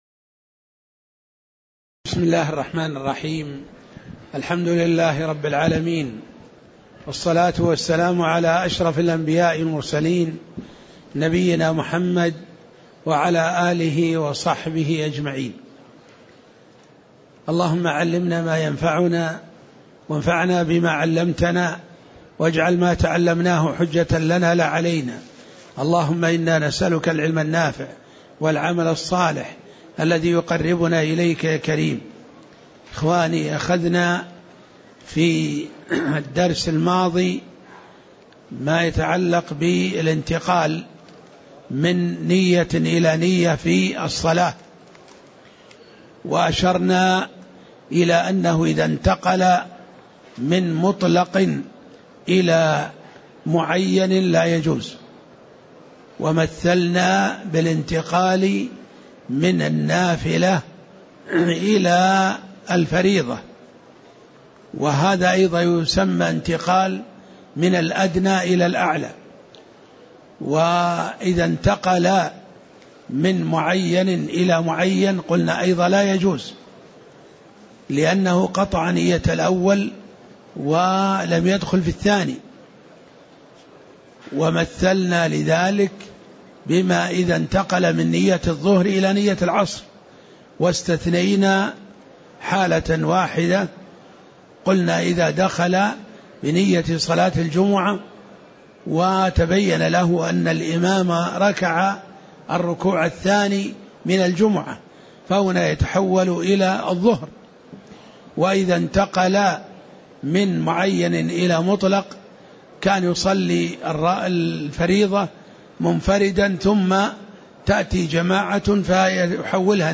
تاريخ النشر ٥ جمادى الأولى ١٤٣٨ هـ المكان: المسجد النبوي الشيخ